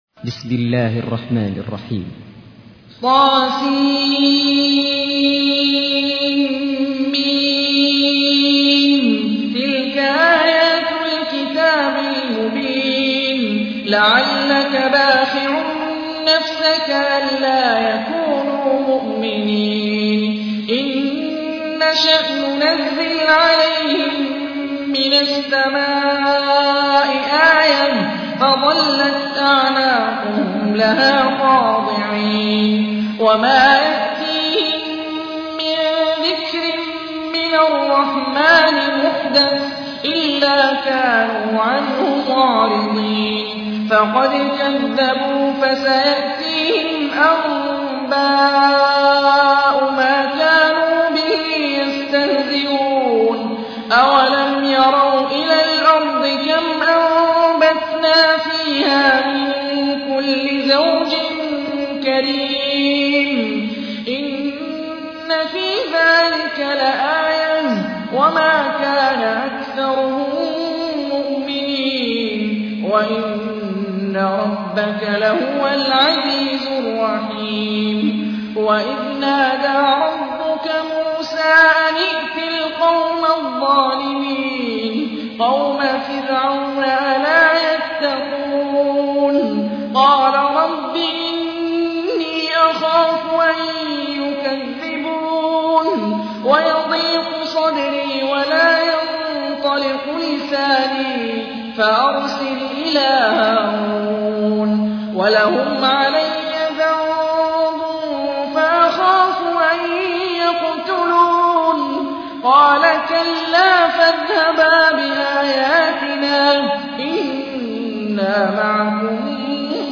تحميل : 26. سورة الشعراء / القارئ هاني الرفاعي / القرآن الكريم / موقع يا حسين